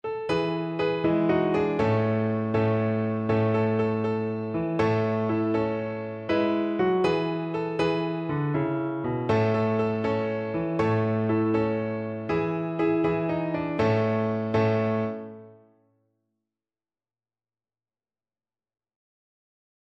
Violin
6/8 (View more 6/8 Music)
A major (Sounding Pitch) (View more A major Music for Violin )
Joyfully .=c.80
Traditional (View more Traditional Violin Music)